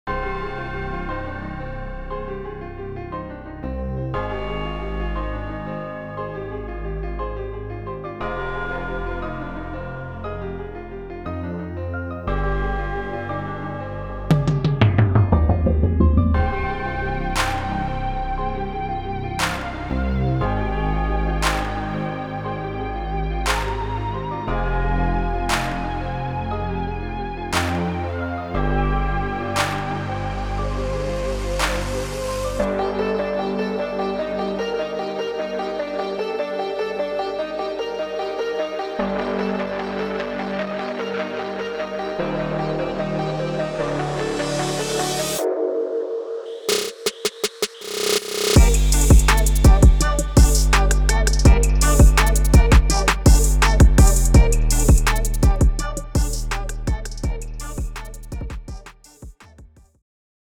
zooms in on deep south trap music with a New Orleans twist.